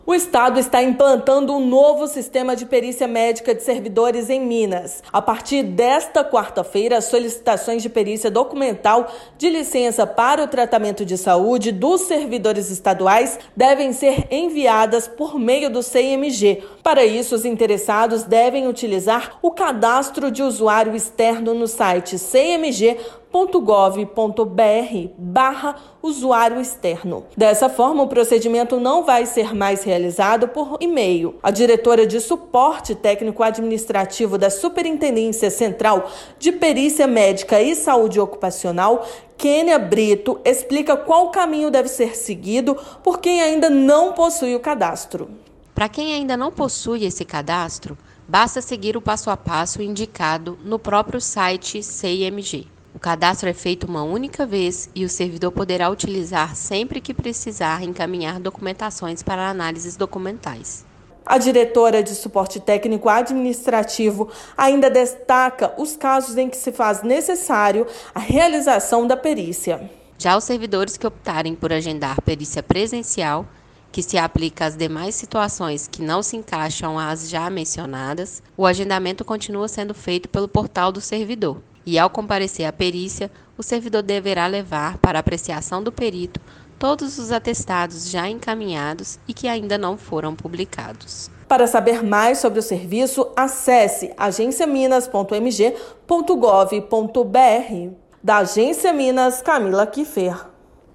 A partir de 1/6, solicitações para perícia de Licença para Tratamento de Saúde (LTS) devem ser feitas via Sei!MG. Ouça matéria de rádio.